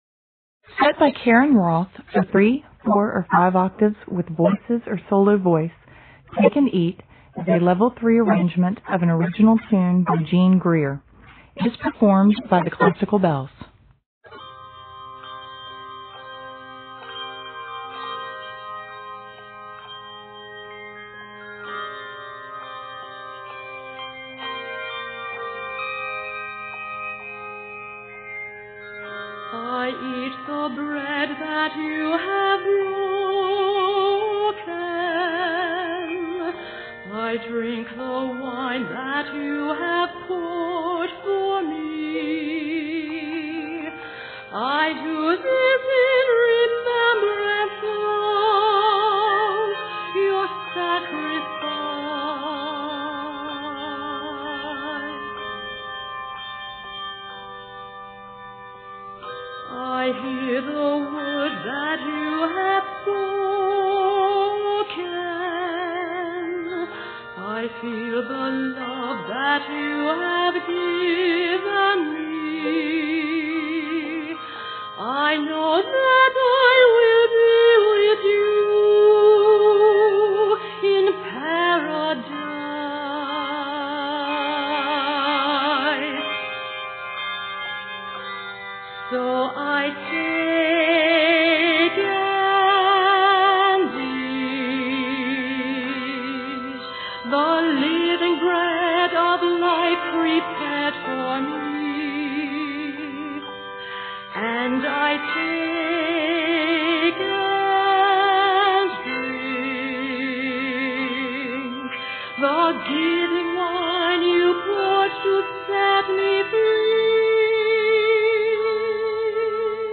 vocal solo that is accompanied by handbells